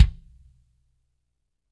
-DRY NS 5 -R.wav